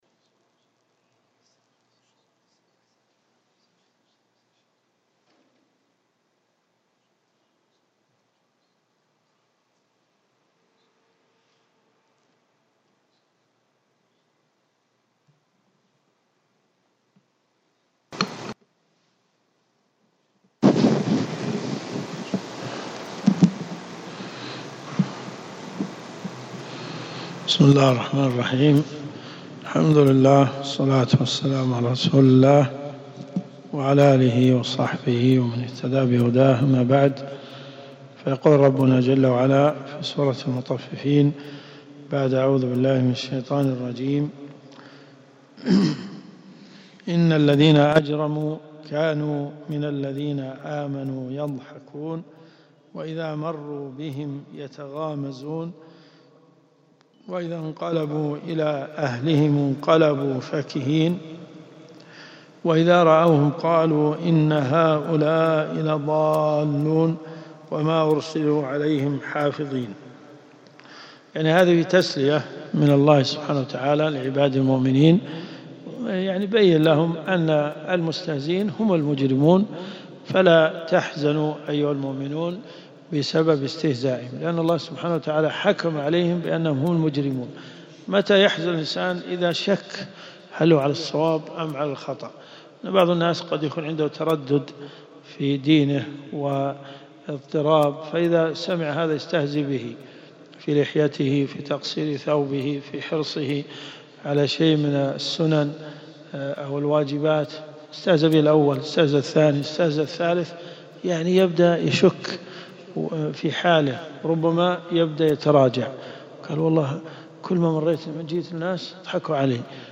تفسير القران الكريم